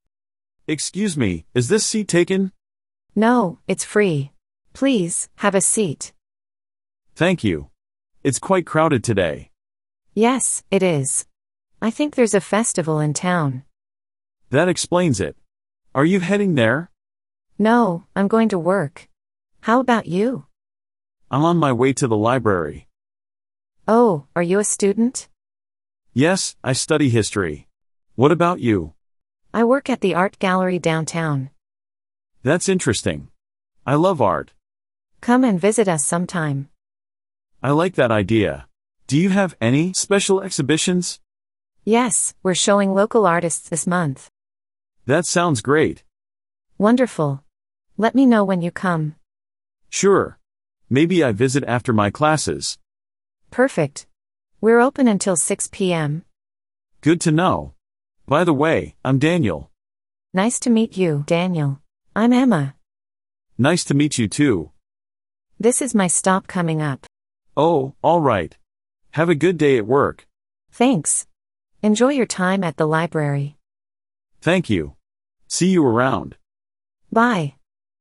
Beginner Listening Practice
Two strangers chat on a crowded bus and discuss visiting an art gallery.